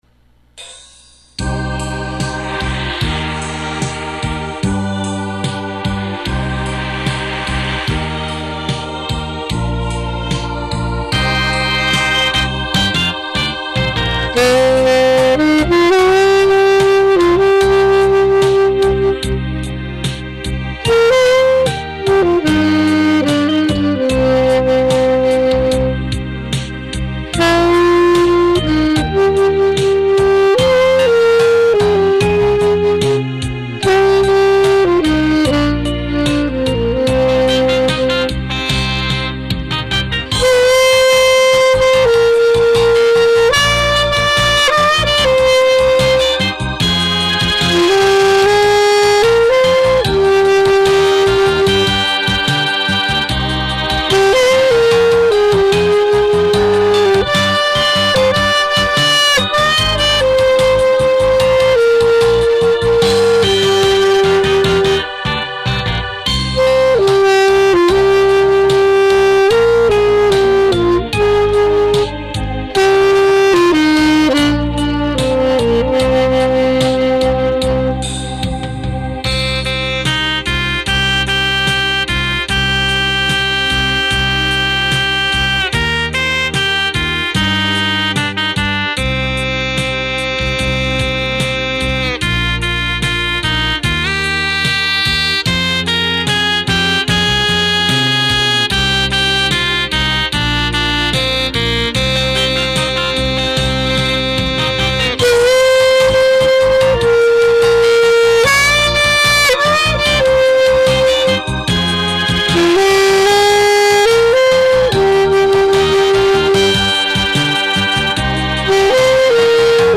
급한대로 곰텡이 프로그램으로  녹음 할려니 에이그 속 디집어져 !
사용악기 :  Cotton 앨토 (Taiwan.)
피스 : 마이어(U.S.A.)
리드 : 반도린 (Vandoren.) 3 호.
반주기 : 윈 스타.
사용 마이크 : 아이와(AIWA) 핀 마이크.